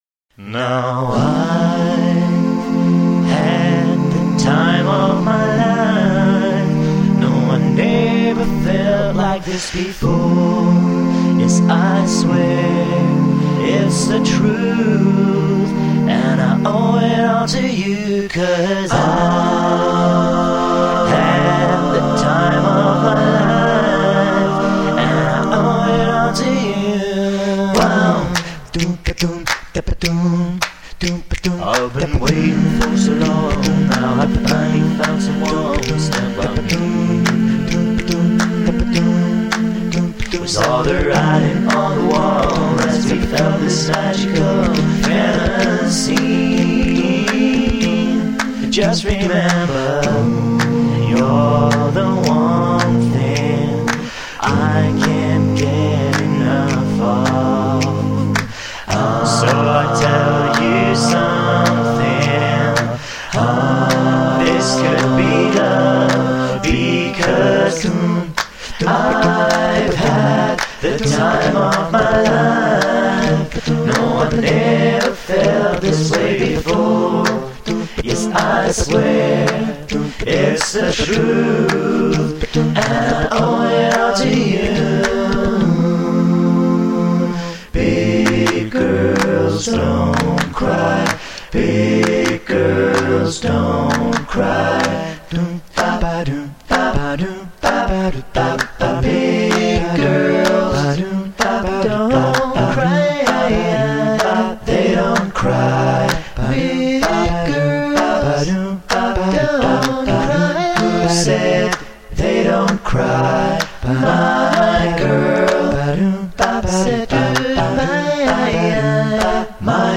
in einem medley
aufgenommen 2005  5-stimmig   4:32 min   4,16 mb